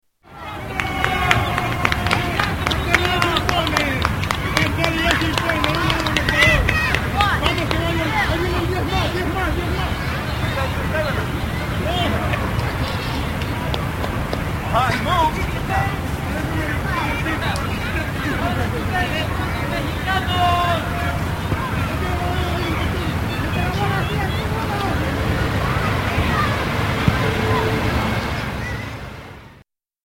NYC central park ambience